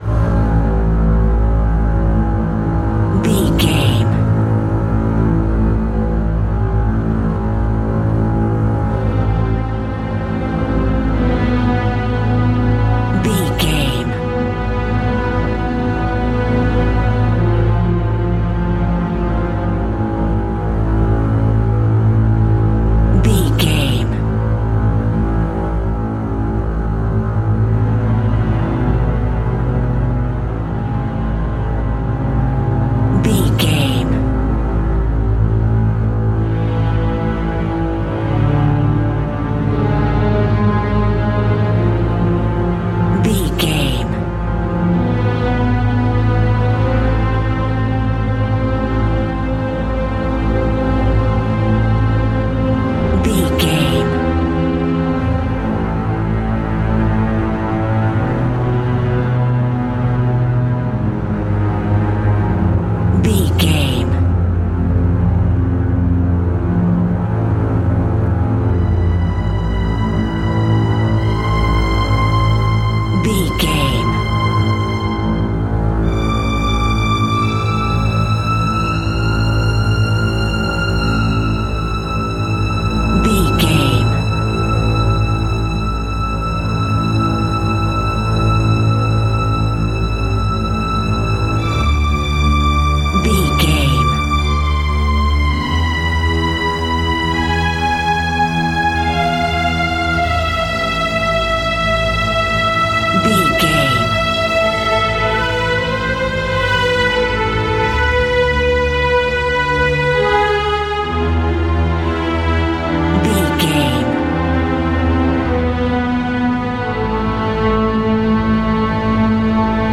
Stringy Horror Music.
Aeolian/Minor
Slow
tension
ominous
eerie
strings
synth
pads